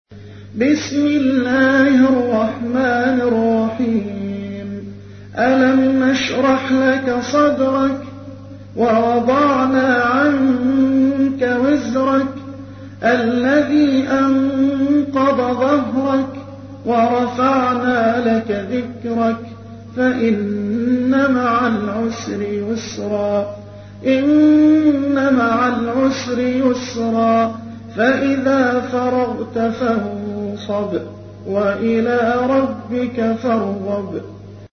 تحميل : 94. سورة الشرح / القارئ محمد حسان / القرآن الكريم / موقع يا حسين